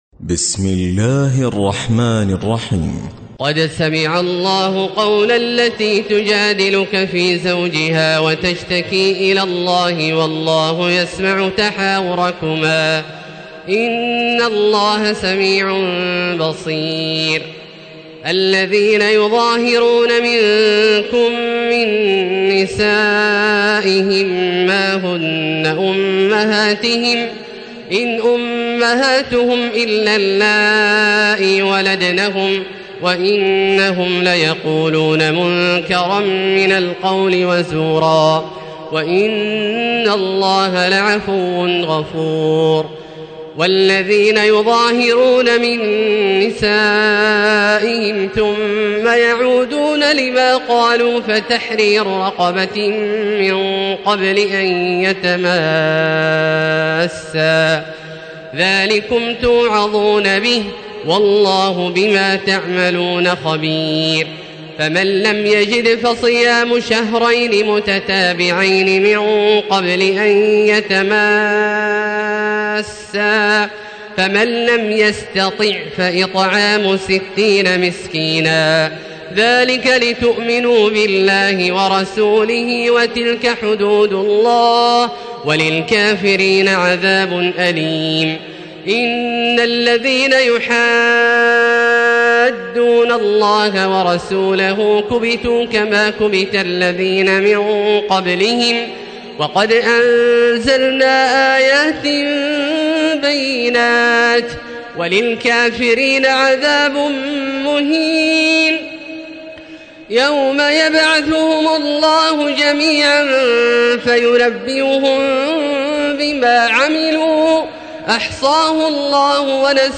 تراويح ليلة 27 رمضان 1437هـ من سورة المجادلة الى الصف Taraweeh 27 st night Ramadan 1437H from Surah Al-Mujaadila to As-Saff > تراويح الحرم المكي عام 1437 🕋 > التراويح - تلاوات الحرمين